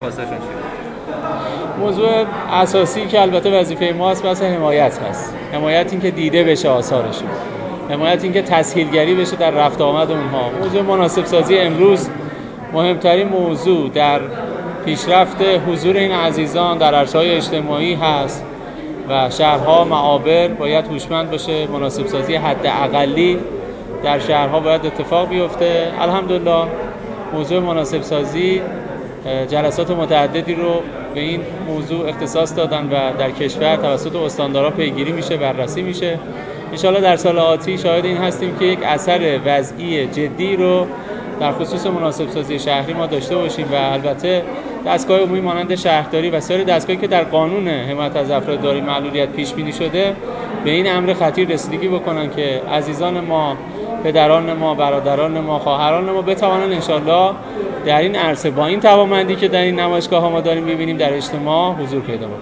رئیس سازمان بهزیستی در گفت‌وگو با ایکنا:
علی محمد قادری، رئیس سازمان بهزیستی کل کشور، در حاشیه بازدید و افتتاحیه دومین جشنواره ملی هنری «همام» در گفت‌وگو با خبرنگار ایکنا گفت: در این نمایشگاه شاهد خلق آثار بزرگی از سوی توانیابان هستیم که می‌تواند برای ارتقای زندگی افراد عادی امیدبخش باشد و نشانه توانمندی‌ ذاتی آنهاست و ما باید از آثار آنها حمایت جدی کنیم.